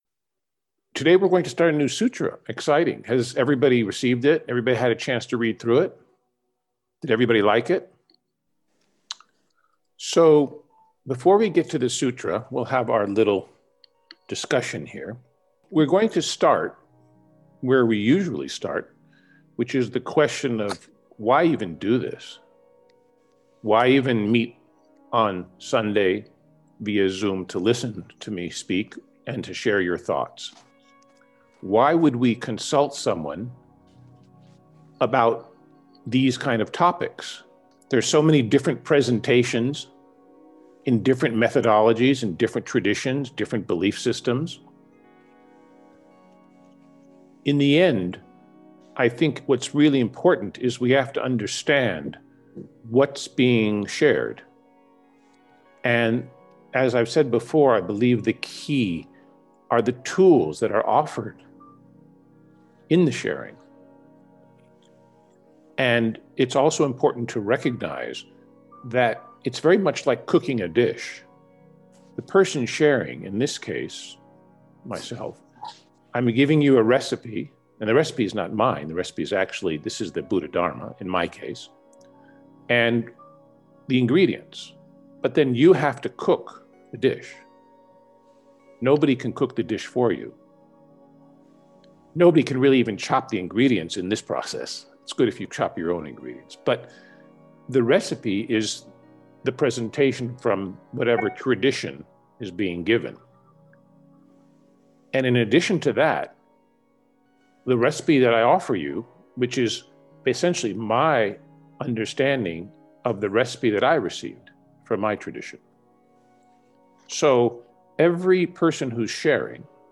Path to Wisdom :: Conversation
path_to_wisdom_discourse.mp3